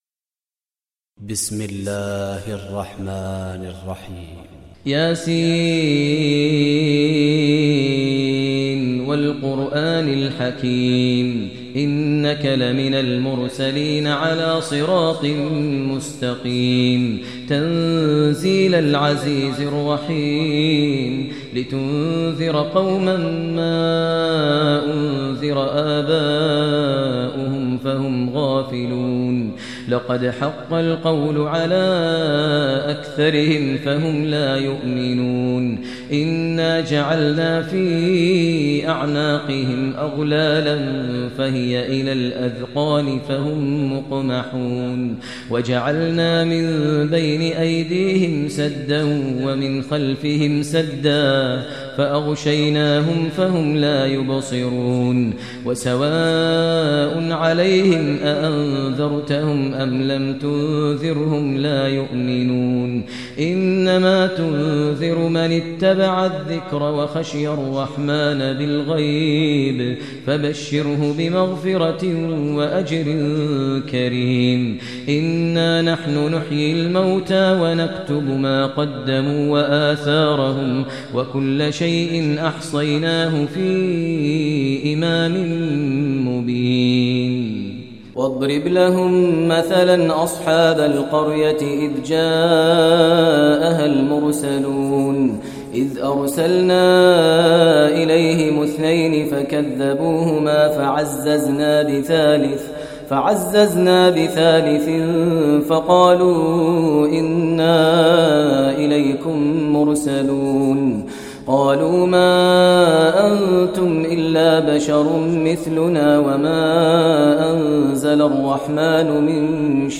Surah Yaseen Recitation by Sheikh Maher al Mueaqly
Surah Yaseen, listen online mp3 tilawat / recitation in the voice of Sheikh Maher al Mueaqly.